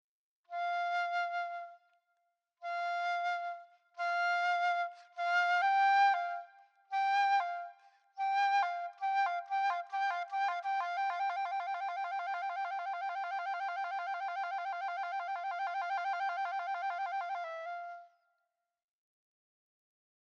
Here’s Beethoven’s flute nightingale again:
This recording of a nightingale (in Germany) appears to start the whistling phrase after about three seconds. It gets louder, speeds up, and erupts into energetic trilling.
beethoven-6-nightingale.mp3